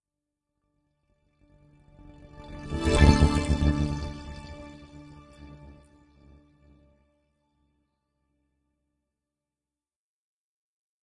描述：一种古典形式，经常用于（早期）电子音乐中。在这种情况下，用处理过的水声构建。
标签： 形式 处理 声音 合成的
声道立体声